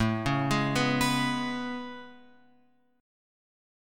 A Major 9th